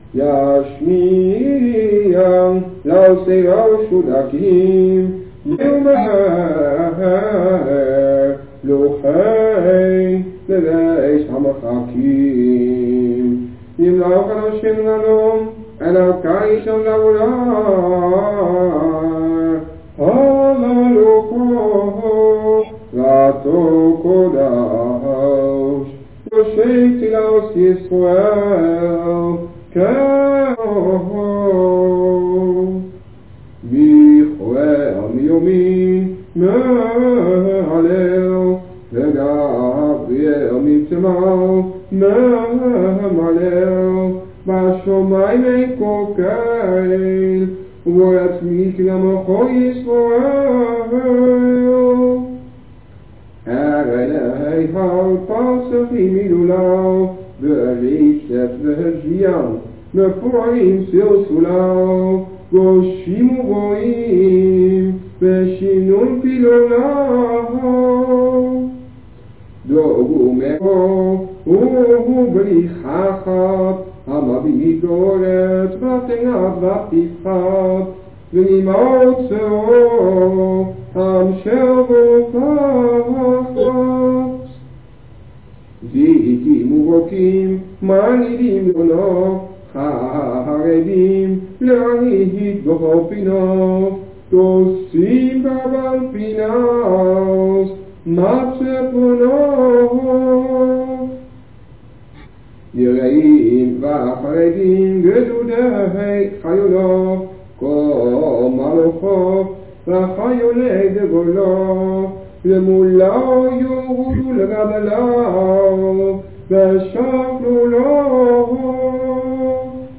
op cassettebandjes